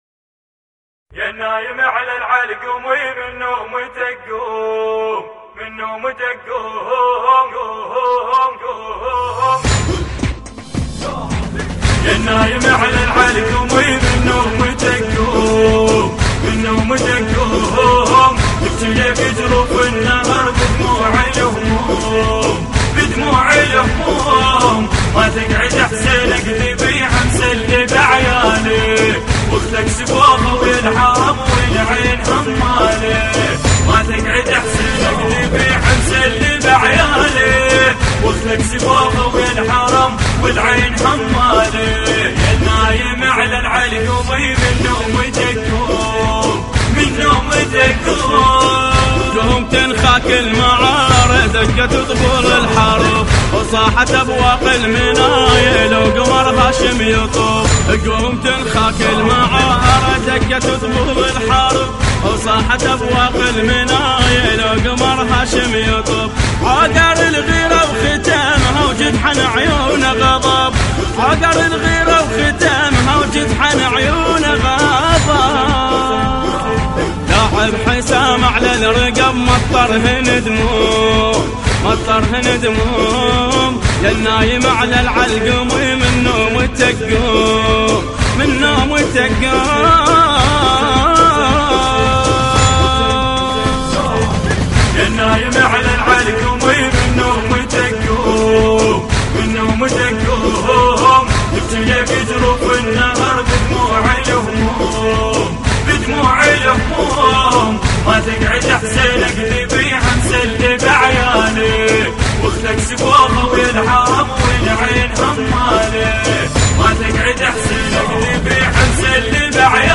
مراثي أبو الفضل العباس (ع)